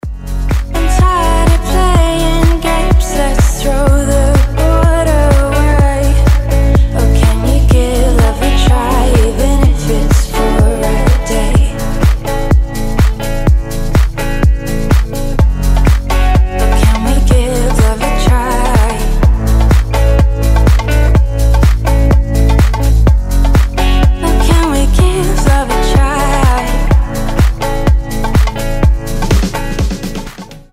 • Качество: 320, Stereo
женский вокал
deep house
tropical house
теплые
духовые